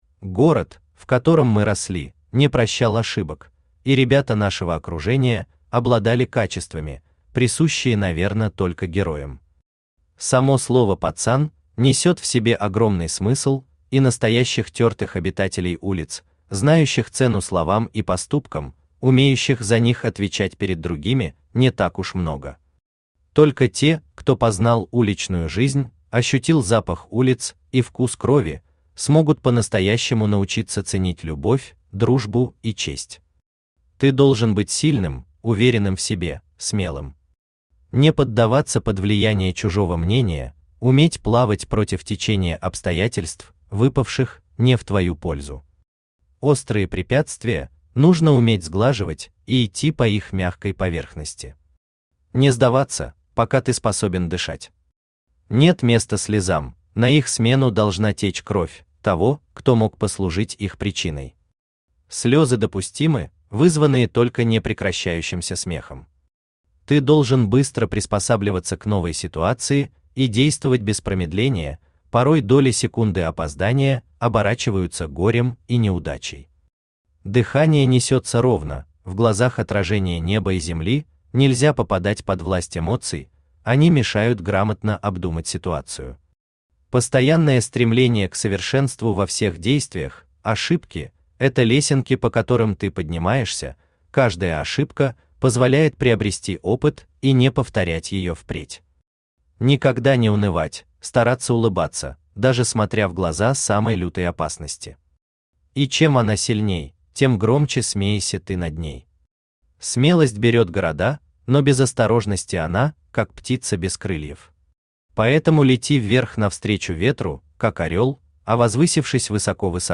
Аудиокнига Каким бывает детство | Библиотека аудиокниг
Aудиокнига Каким бывает детство Автор Евгений Михайлович Казьмин Читает аудиокнигу Авточтец ЛитРес.